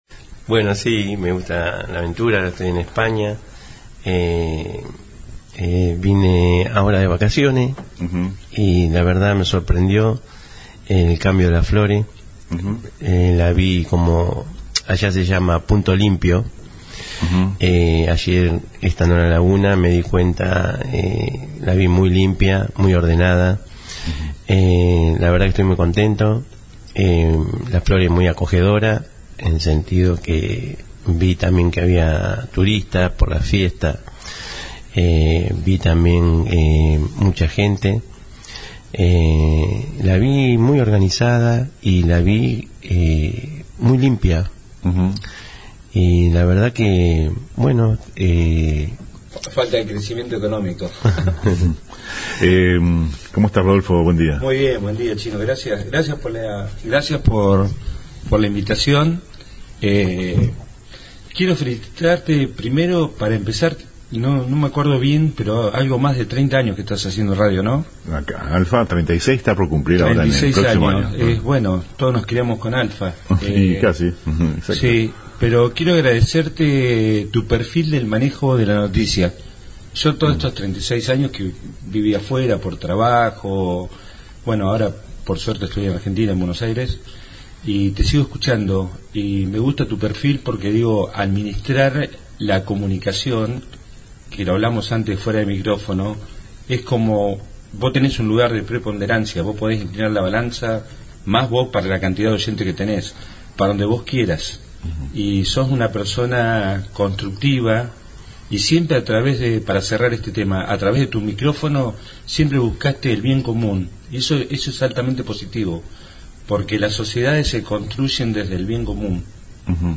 En una entrevista de color, hoy por la mañana visitaron el estudio de la radio